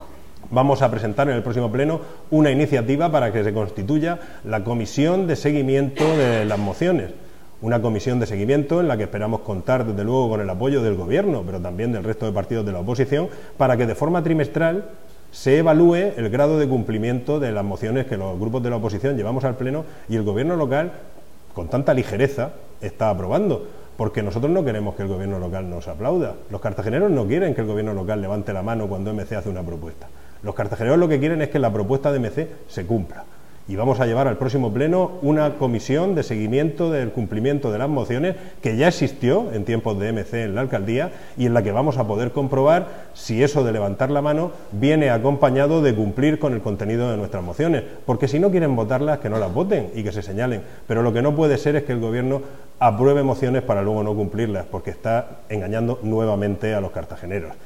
Audio: Declaraciones de Jes�s Gim�nez. (MP3 - 13,24 MB)